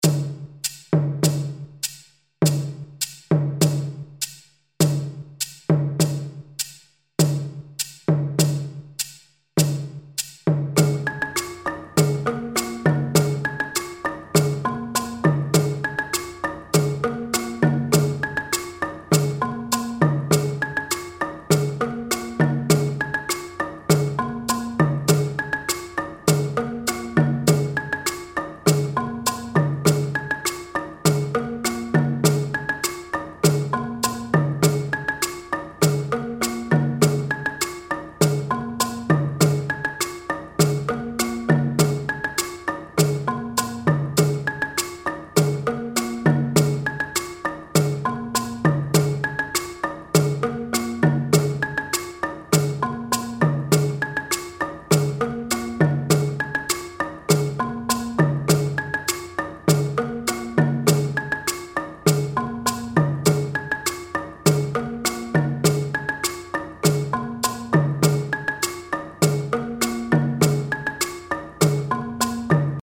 Pentatonische balafoon
201 bpm Patroon A
RitmeMuso-Bwe-201bpm-Patroon-A.mp3